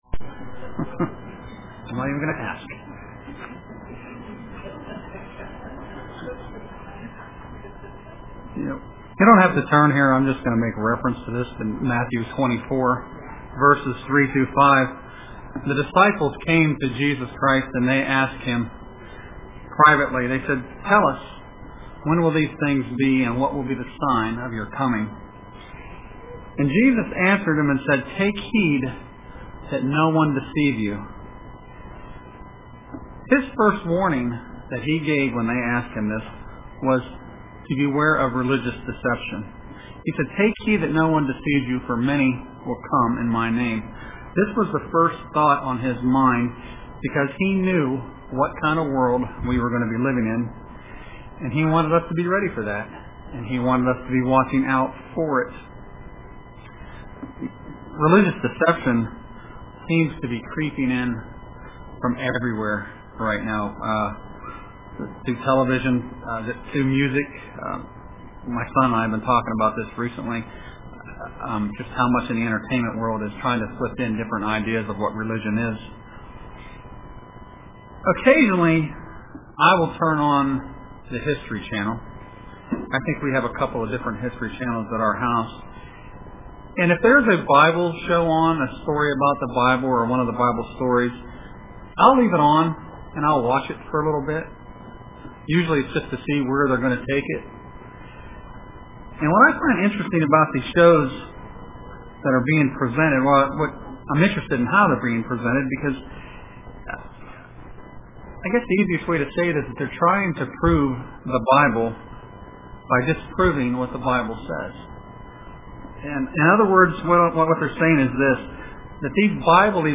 Print Avoiding Religious Deception UCG Sermon Studying the bible?